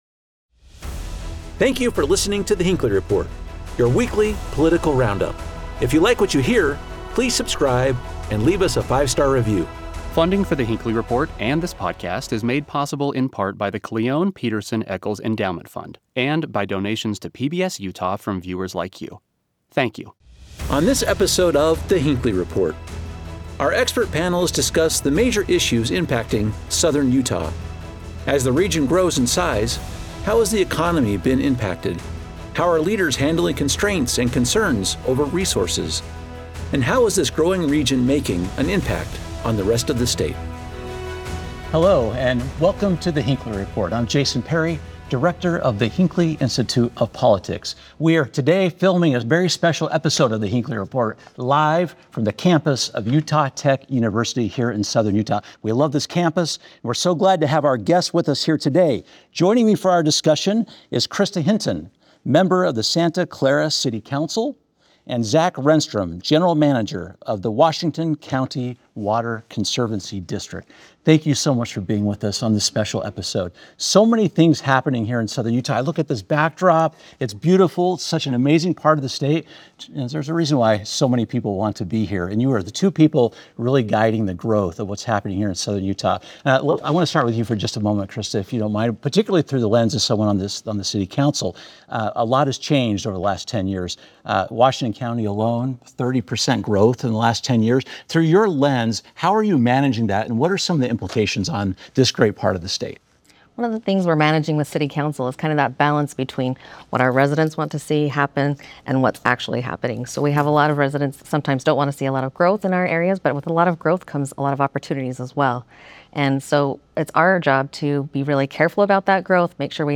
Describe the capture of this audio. On this special episode of The Hinckley Report, we're on location in St. George.